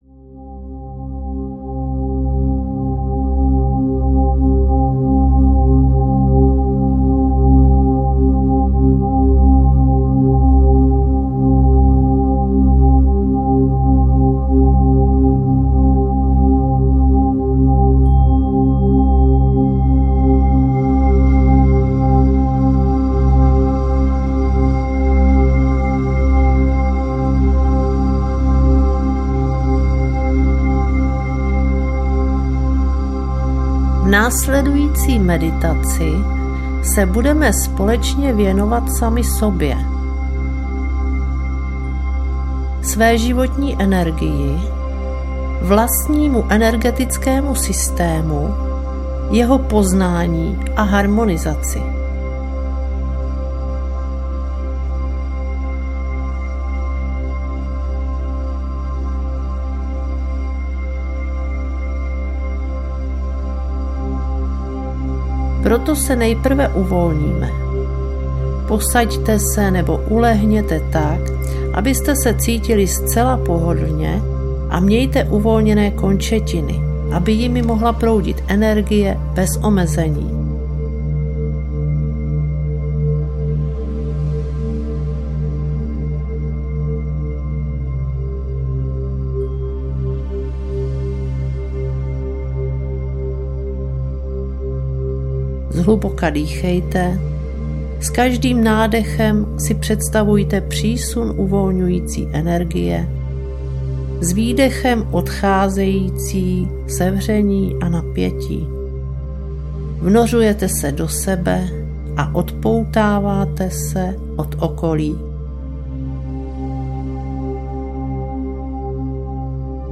Meditace - životní energie, harmonizace čaker audiokniha
Ukázka z knihy